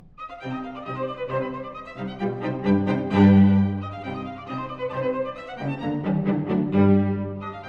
↑古い録音のため聴きづらいかもしれません！（以下同様）
ロンド形式といって、最初に現れたテーマを、曲全体でなんども回帰させるスタイルを取っています。
合間に穏やかなフレーズが訪れるが、結局は暗い第1テーマに戻ってしまう・・・全体的に哀しく引き締まった楽章です。
ただし、一番最後の数小節は、なんと長調で終わるのです。